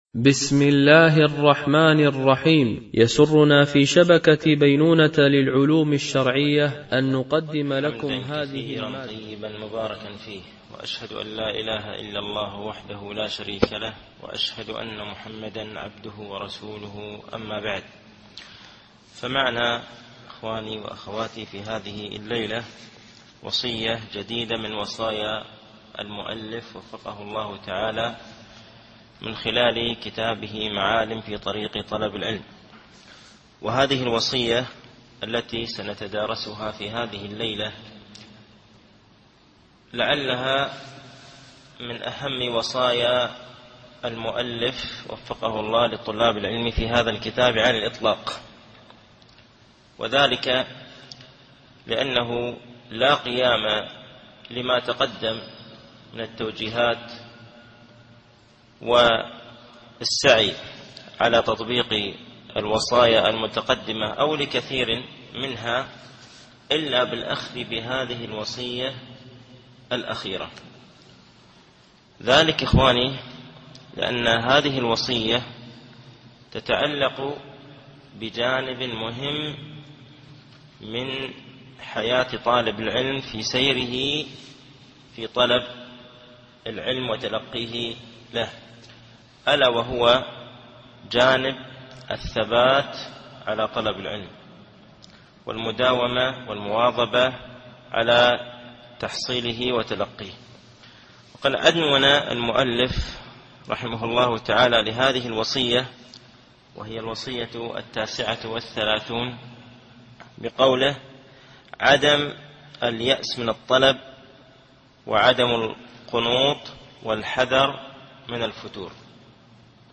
التعليق على كتاب معالم في طريق طلب العلم (الوصية 39 الاستمرار على طلب العلم 1) - الدرس الرابع و السبعون